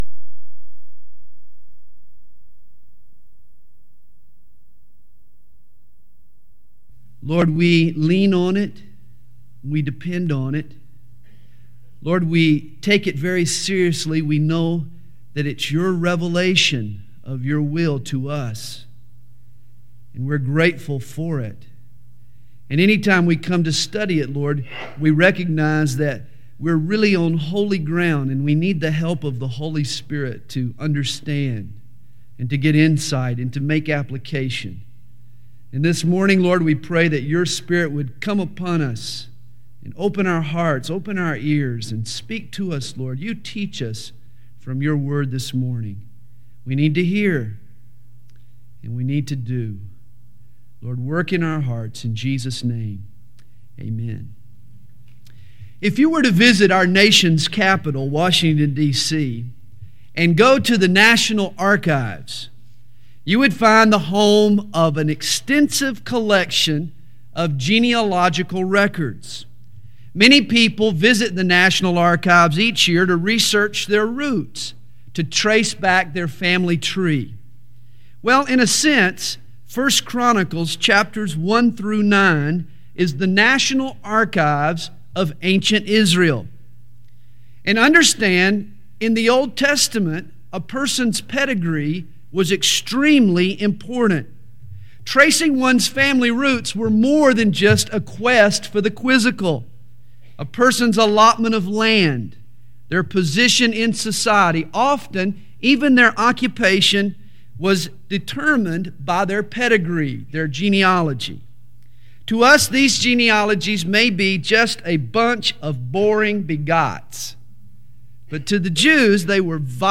2000 DSPC Conference: Pastors & Leaders Date